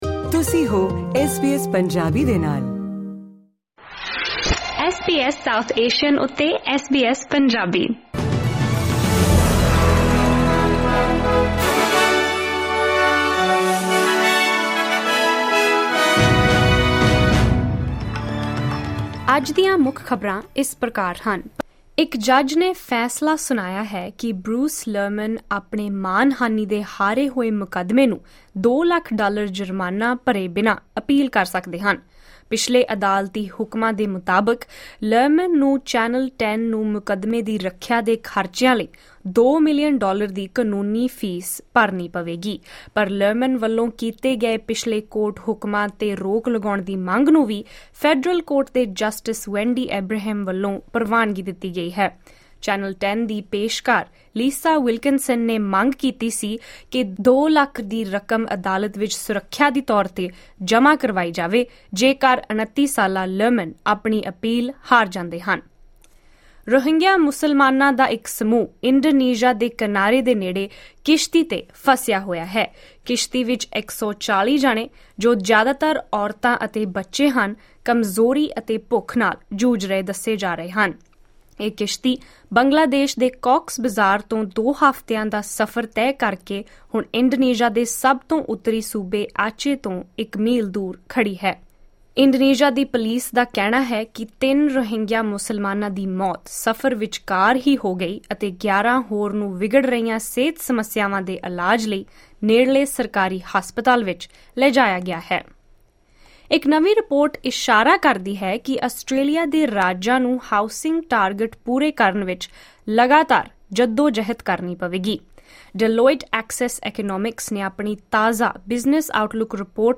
ਐਸ ਬੀ ਐਸ ਪੰਜਾਬੀ ਤੋਂ ਆਸਟ੍ਰੇਲੀਆ ਦੀਆਂ ਮੁੱਖ ਖ਼ਬਰਾਂ: 23 ਅਕਤੂਬਰ 2024